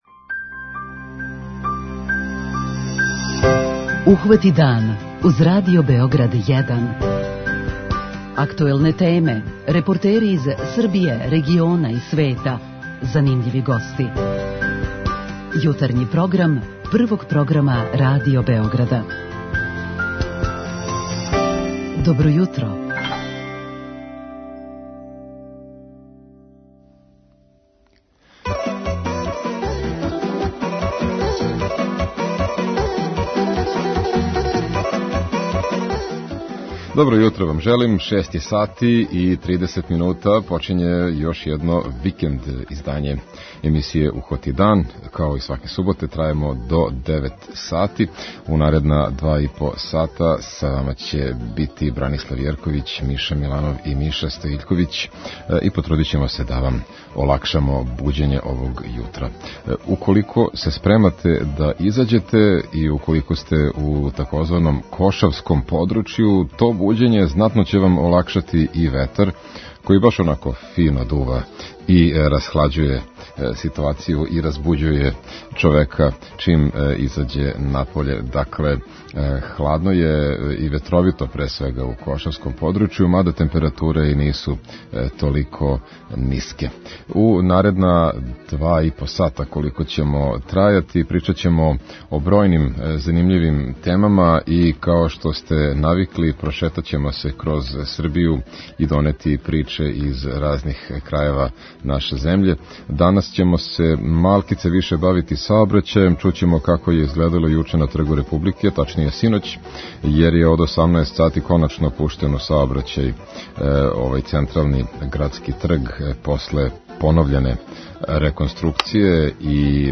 Сазнаћемо и како је Врање постало члан Унескове мреже креативних градова, а чућемо и репортажу о Дринској дивизији коју је наша екипа направила на Крфу.